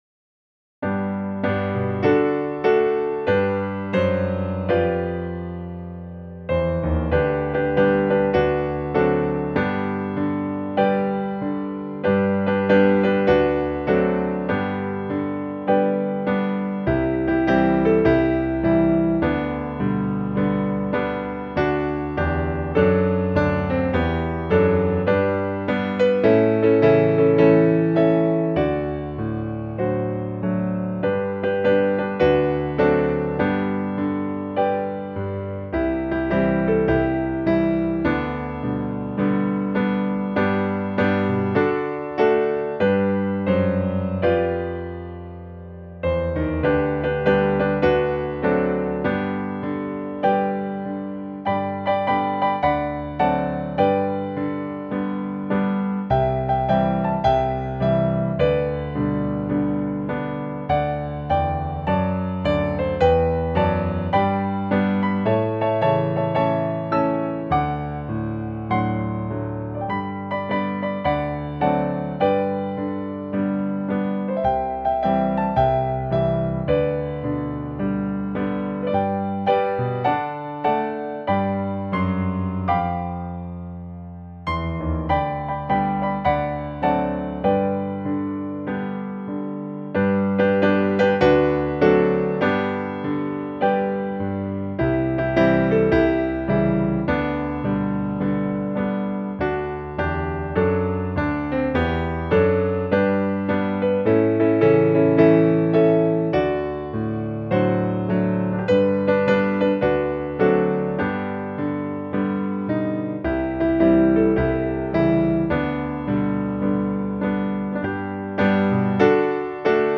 MAGNIFY THE LORD WITH ME -Traditional Hymns Podcast